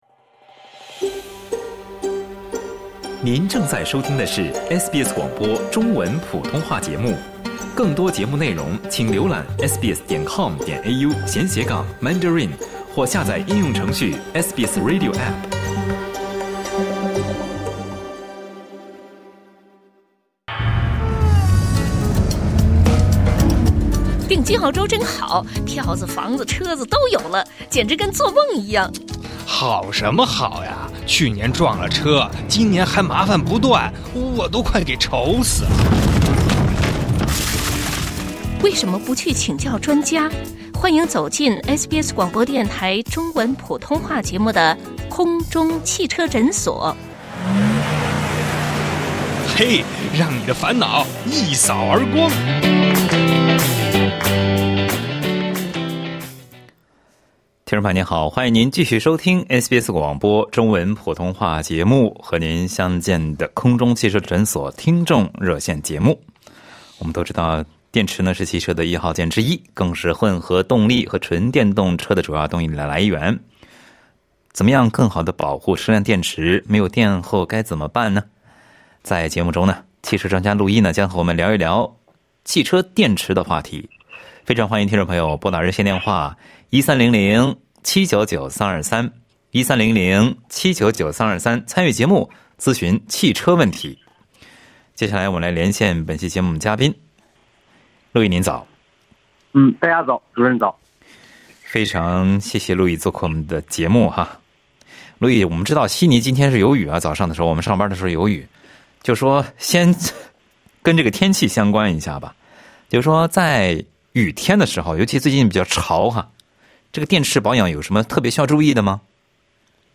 爱车保养、故障判断、解答车辆养护疑问，欢迎您参与SBS普通话节目《空中汽车诊所》热线节目，咨询汽车问题。